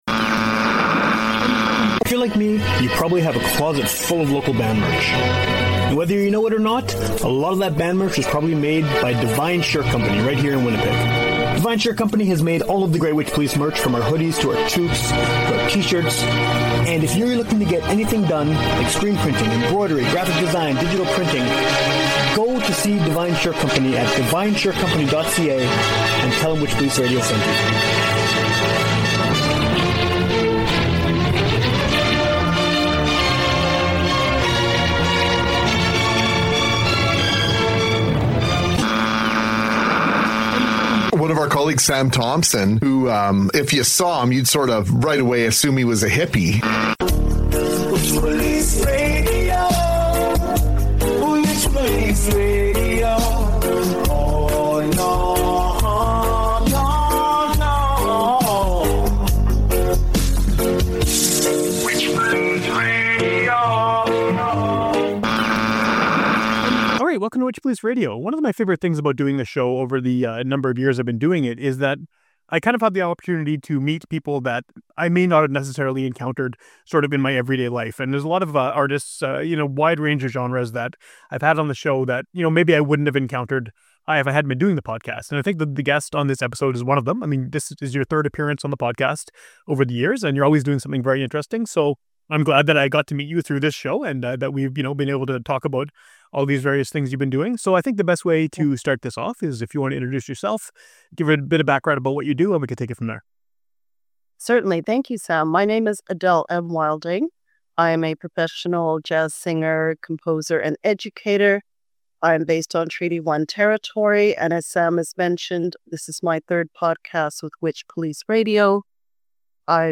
Acclaimed Manitoba-centric music interview podcast.
Music talk radio, broadcasting from 2012 'til forever.